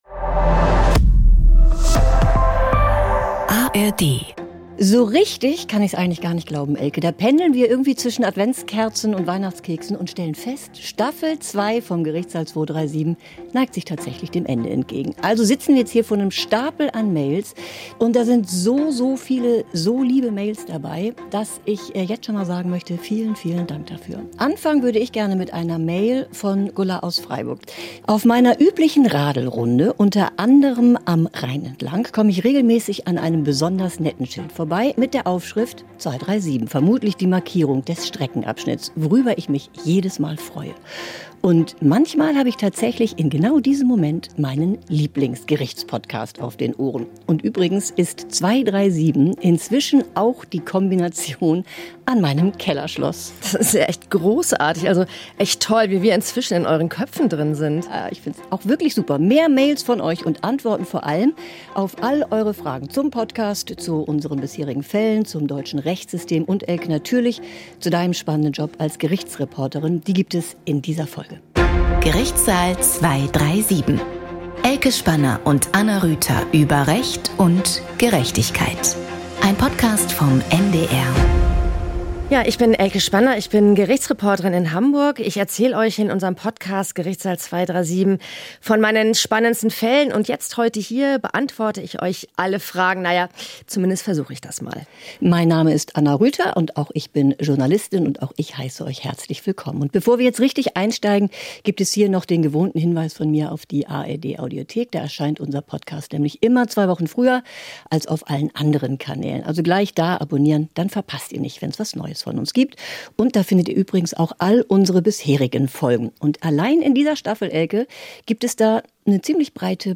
Q&A: Fallupdates, Jobfragen, exklusive Einblicke (37) ~ Gerichtssaal 237. True Crime aus dem Strafgericht Podcast